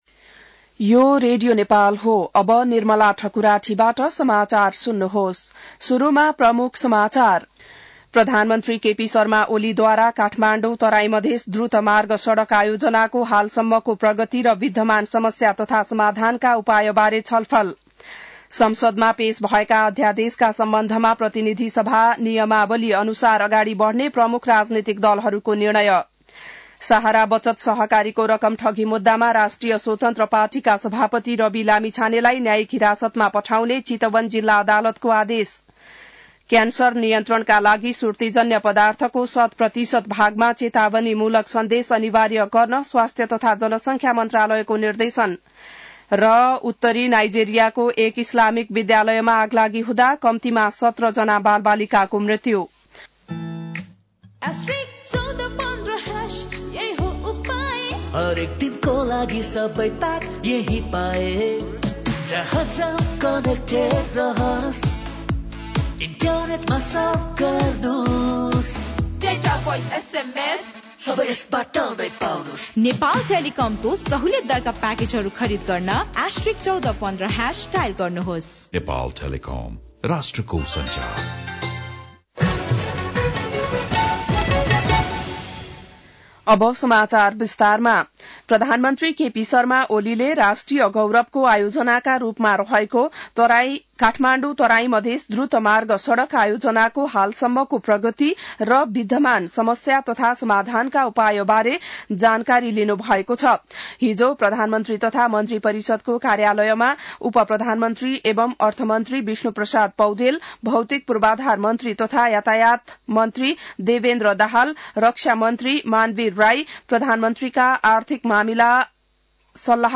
बिहान ७ बजेको नेपाली समाचार : २५ माघ , २०८१